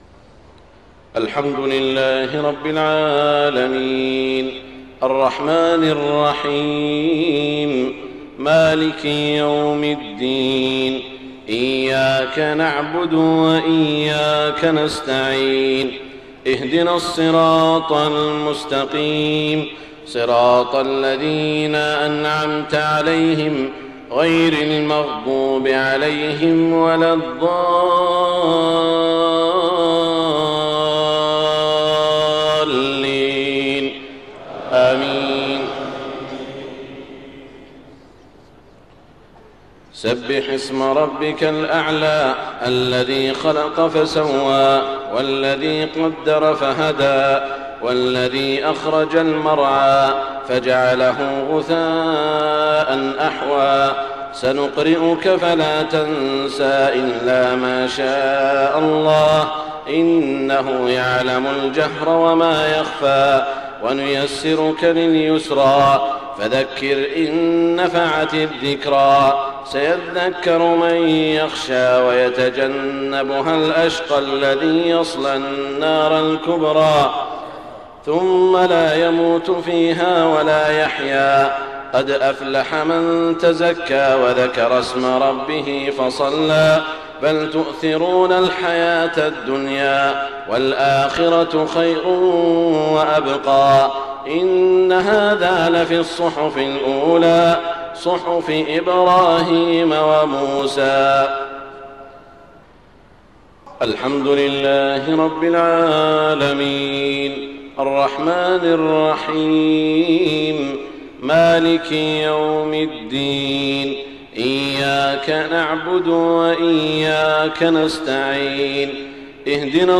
صلاة الجمعة 7 شعبان 1429هـ سورتي الأعلى و الغاشية > 1429 🕋 > الفروض - تلاوات الحرمين